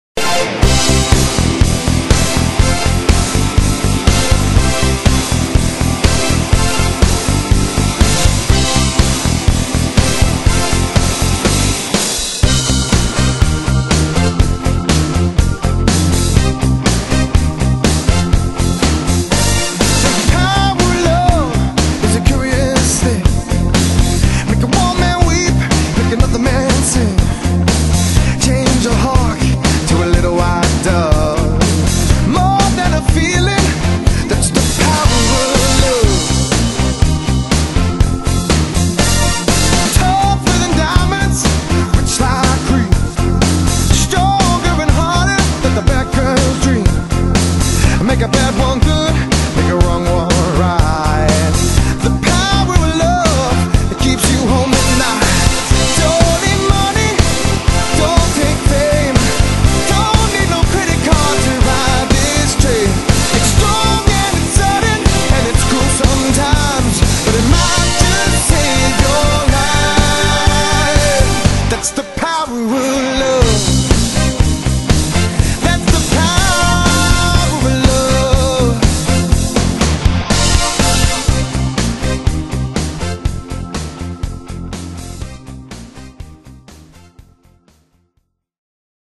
Melbourne’s No.1 Cover Band
guitar and vocals